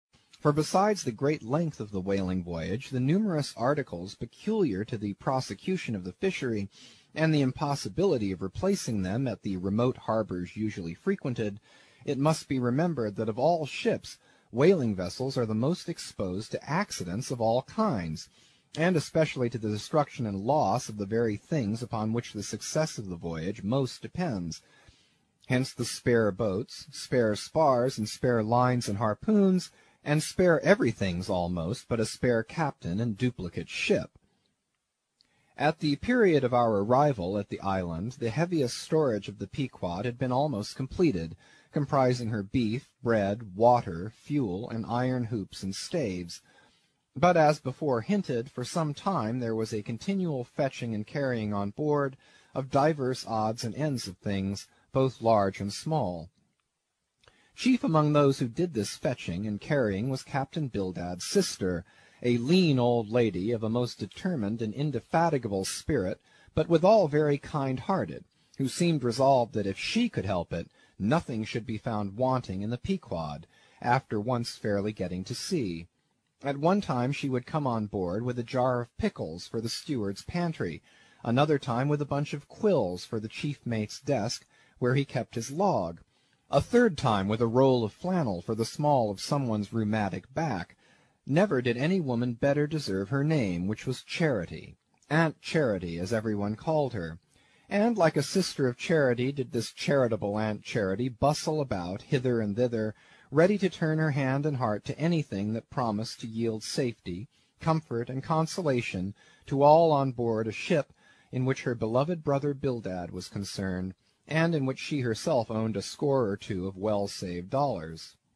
英语听书《白鲸记》第319期 听力文件下载—在线英语听力室